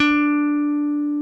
D4 2 F.BASS.wav